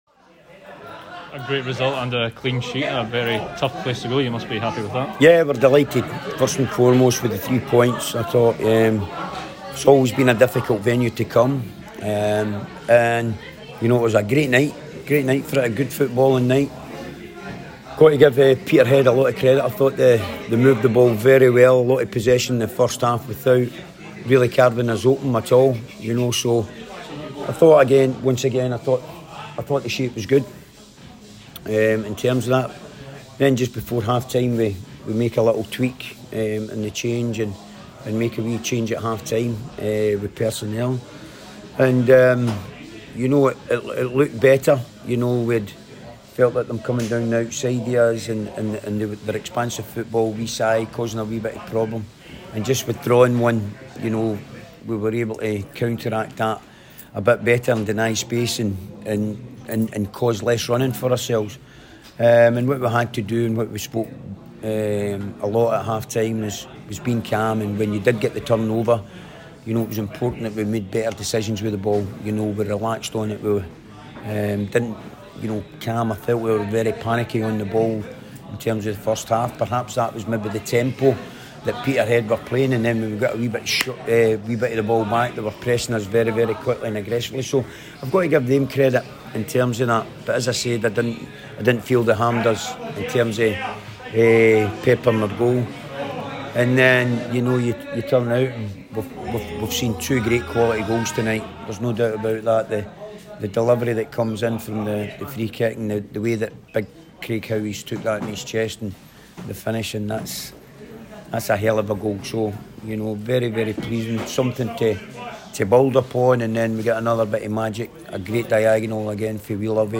press conference after the League 1 match.